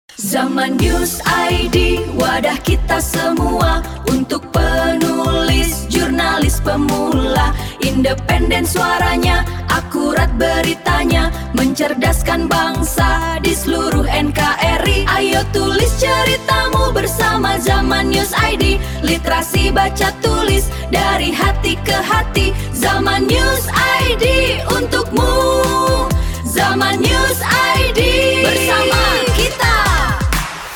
Suara_Menulis_ZamanNewsID.mp3